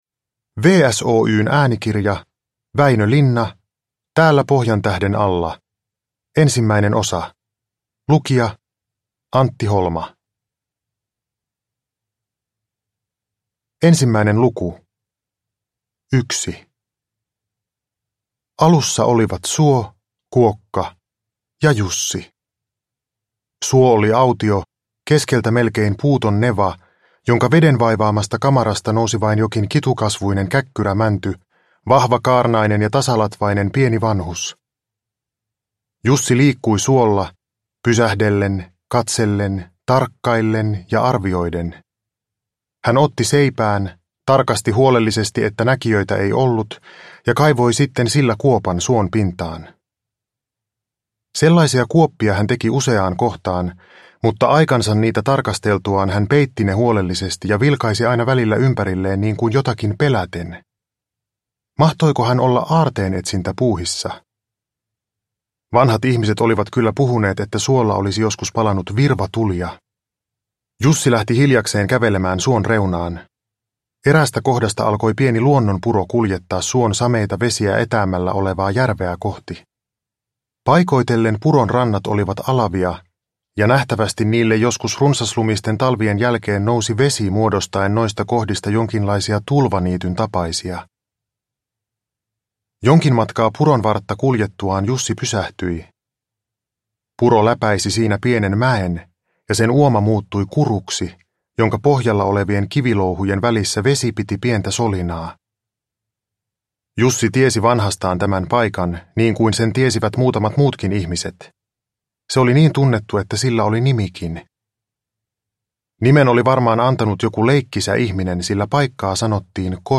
Täällä Pohjantähden alla 1 – Ljudbok – Laddas ner
Uppläsare: Antti Holma